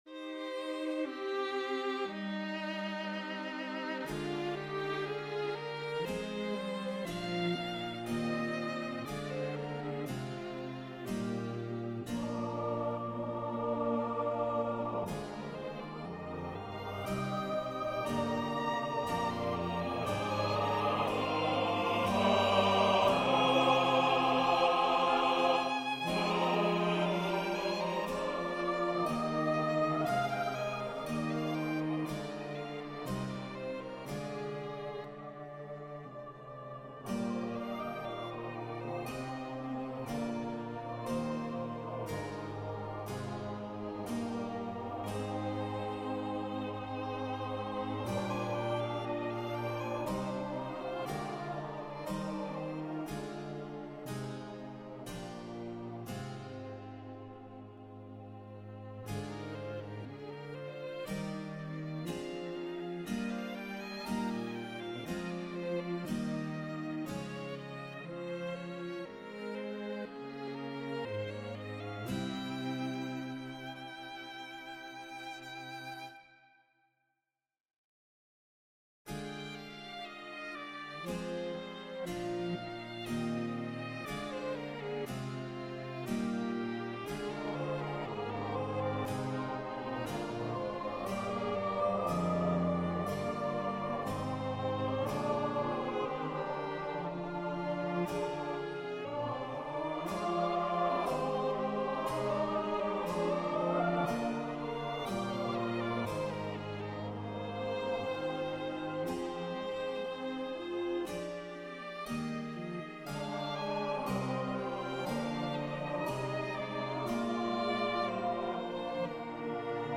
Number of voices: 4vv Voicing: SATB Genre: Sacred, Anthem
Language: English Instruments: Mixed ensemble
This composition is for SATB choir with string accompaniment (Violin, Viola, Cello, and Harpsicord) using the Locrian Mode.